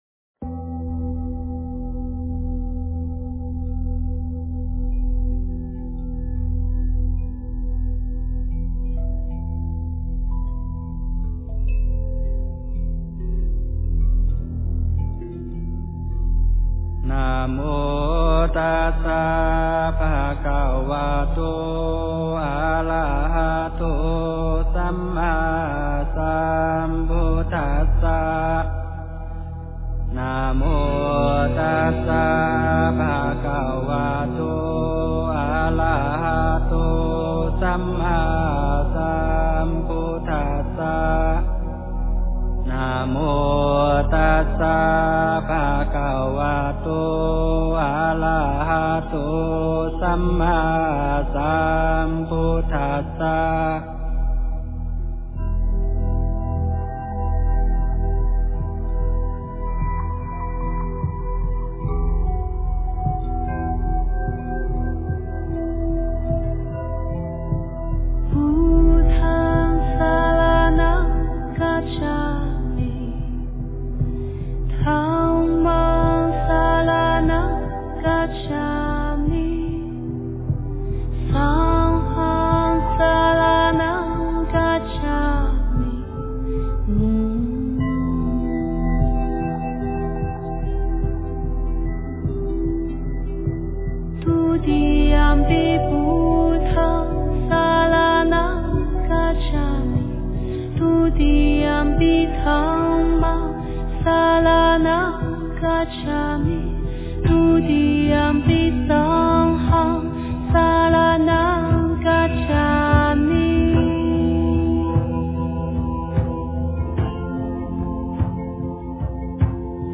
佛音 真言 佛教音乐 返回列表 上一篇： 楞严咒(快诵